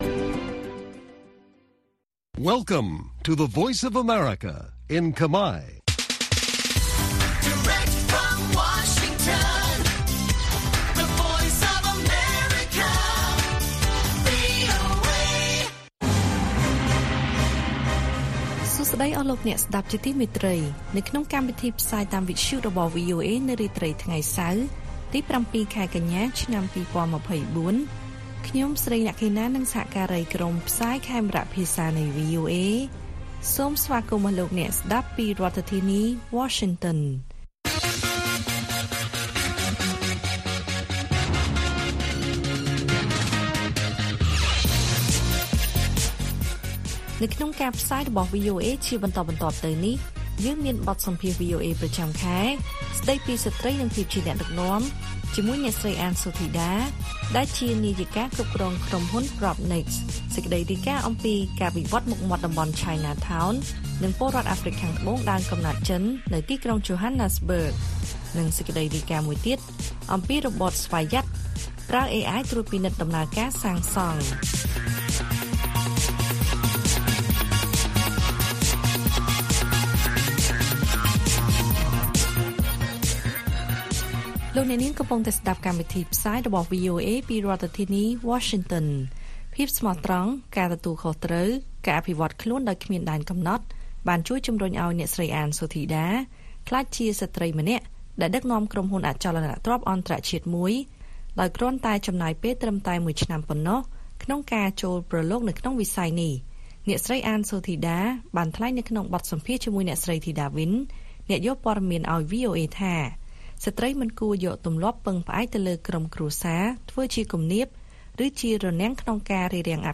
ព័ត៌មានពេលរាត្រី ៧ កញ្ញា